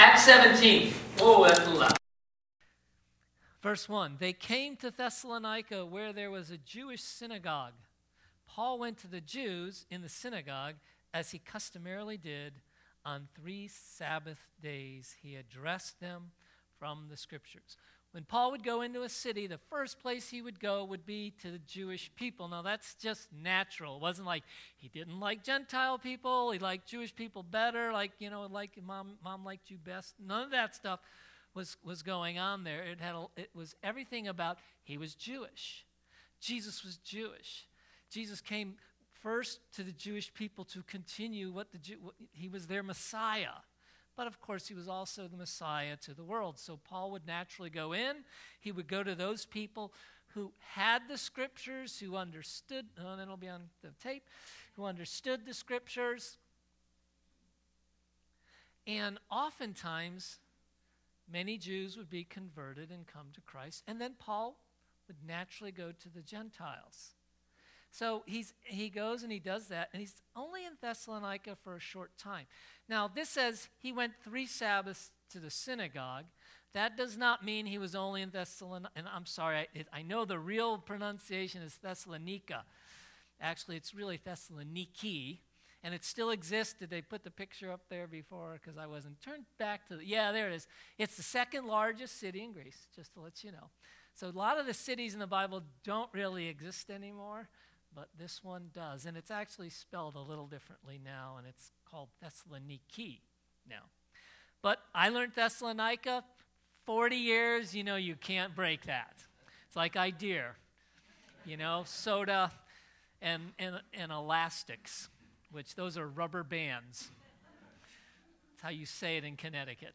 Audio on the video improves after 80 seconds!